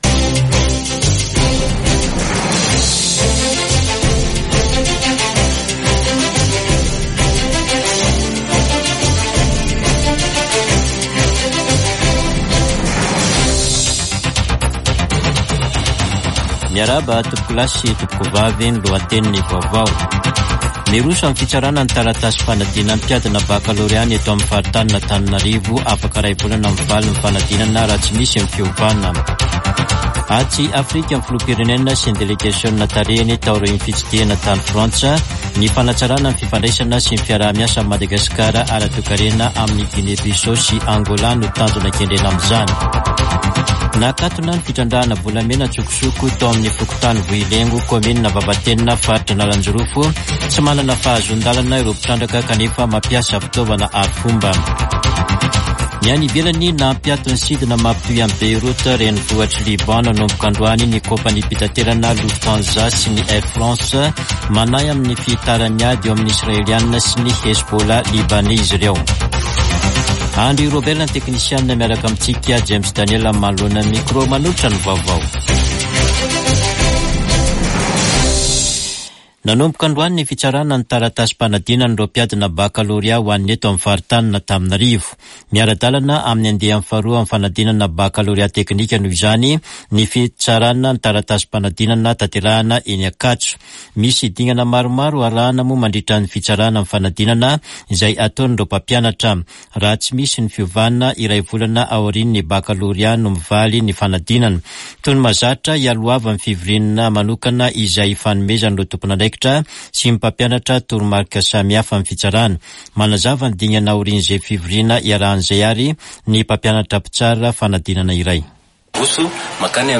[Vaovao hariva] Alatsinainy 29 jolay 2024